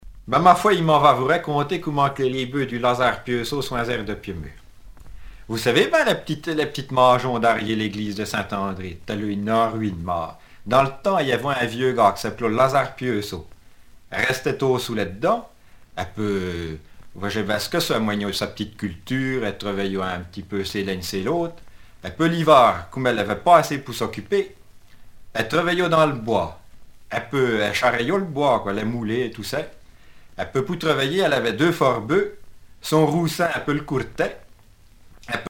Mémoires et Patrimoines vivants - RaddO est une base de données d'archives iconographiques et sonores.
Genre sketch
Catégorie Récit